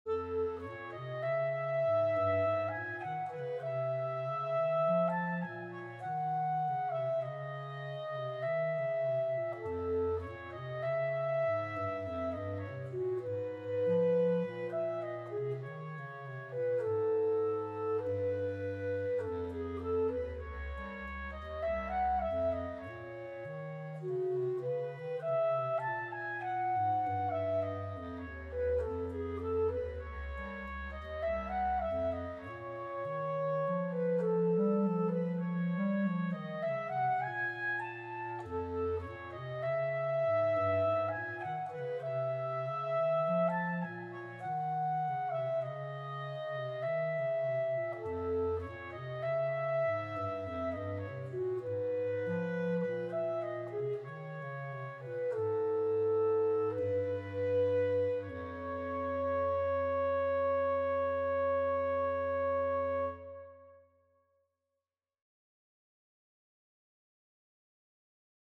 Persichetti Exercise 2 - 56 for Clarinet Duo
I chose to change the instrumentation to Bass Clarinet and Sopranino Clarinet a...